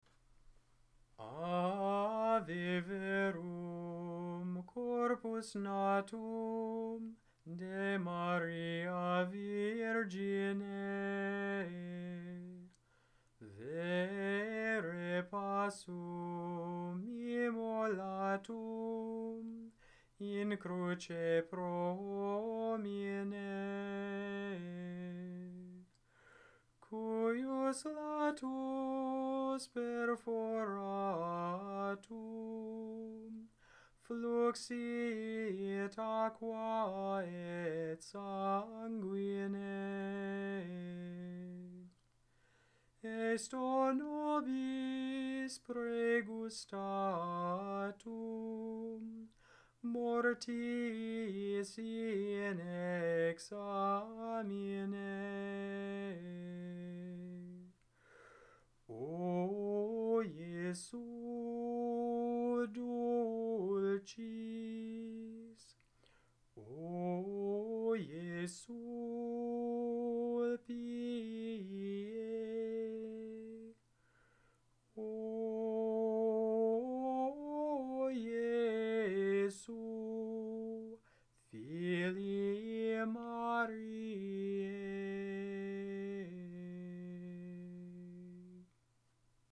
Gregorian, Catholic Chant Ave Verum Corpus